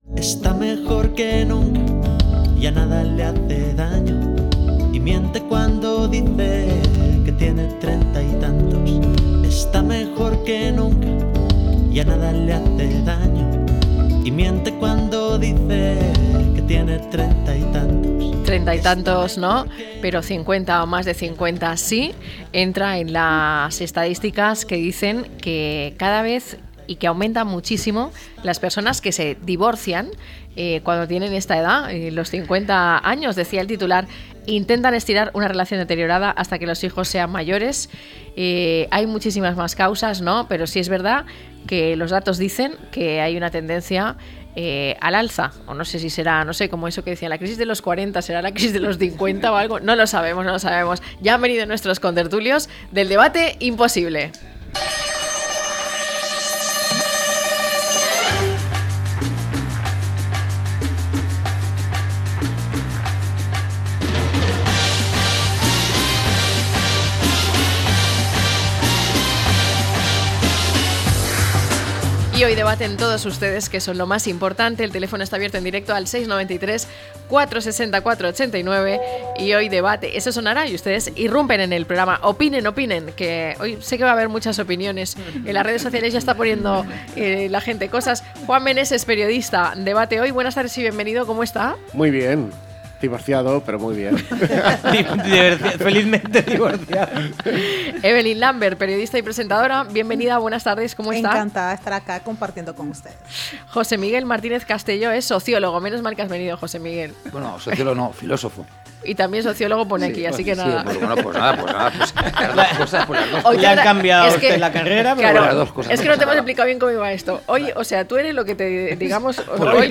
Los divorcios a partir de los 50, a debate - La tarde con Marina